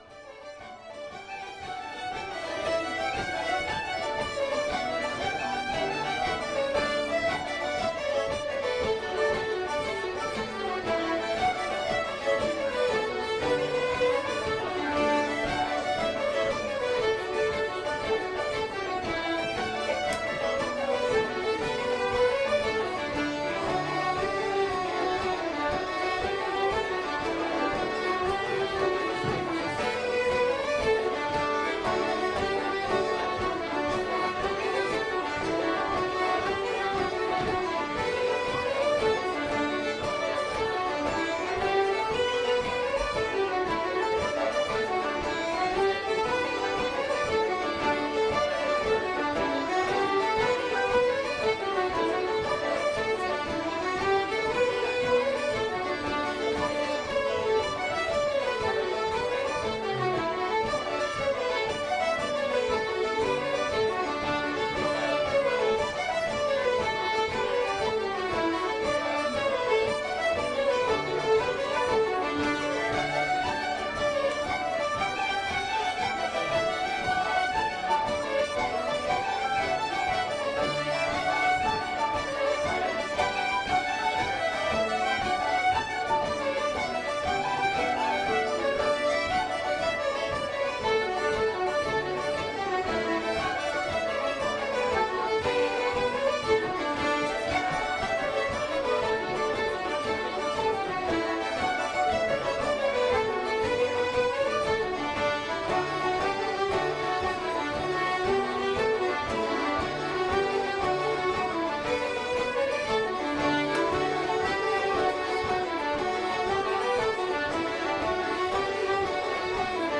Live Irish Music at McGrath's Pub (Audio)
Over twenty people participating in an open session during Catskills Irish Arts Week 2012.